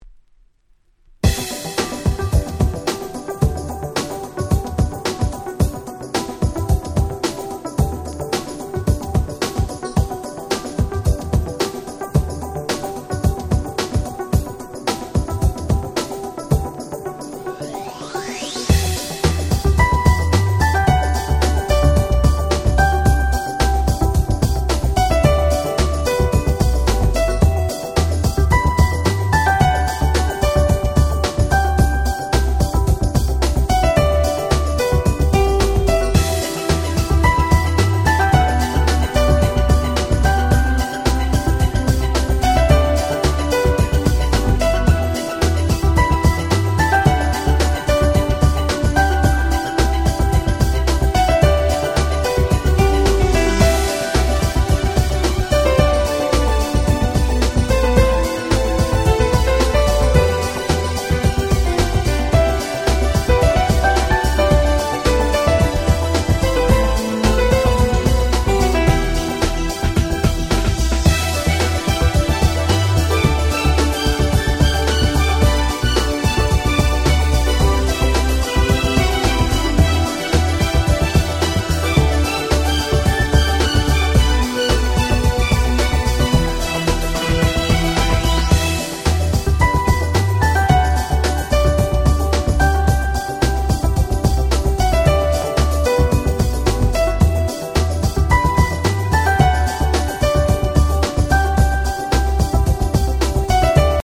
97' Nice Ground Beat !!
こちらは美しいメロディーのGround Beatナンバーなのです！！